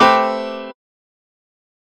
01_Jazzy.wav